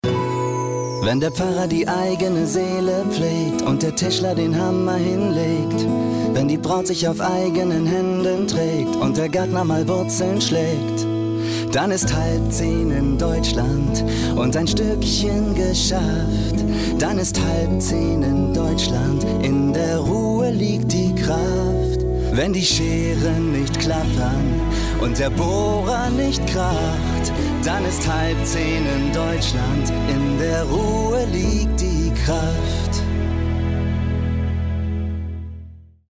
Vocals (Gesang)